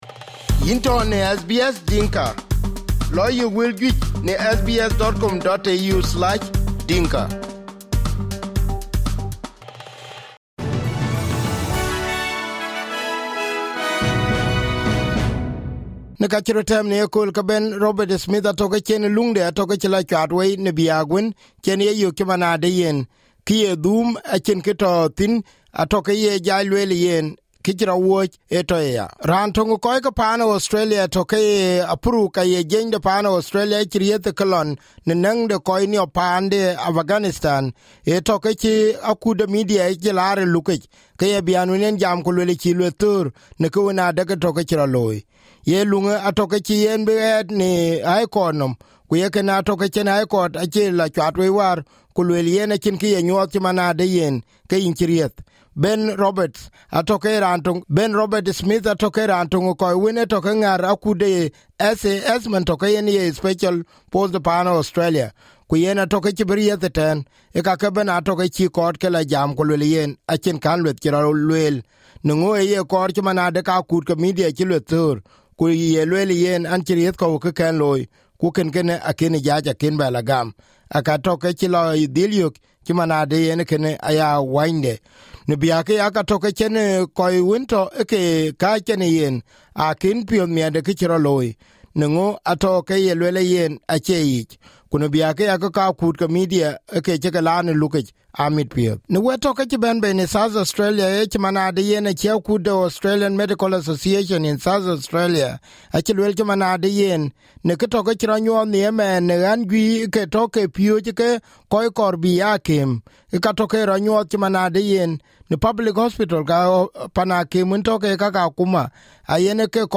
SBS Dinka News Bulletin 02/06/2023